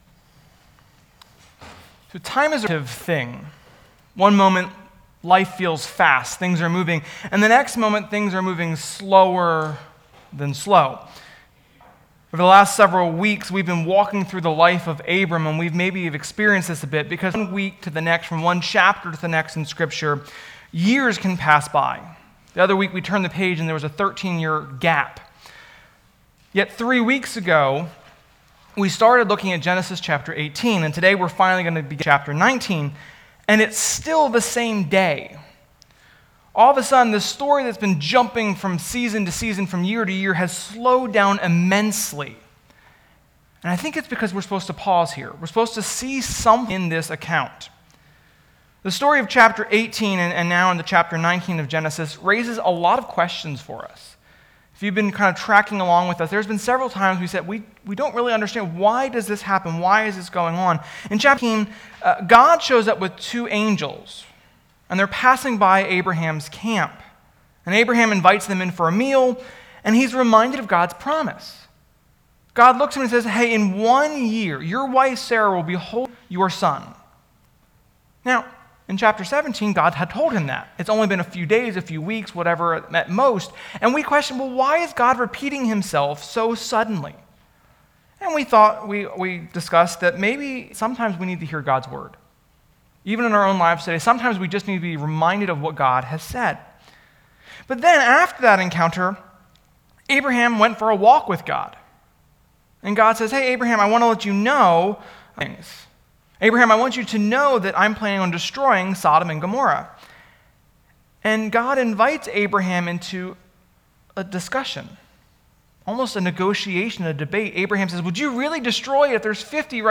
FEEL FREE TO LISTEN TO THE AUDIO ONLY FILE BELOW – IT HAS A CLEANER SOUND.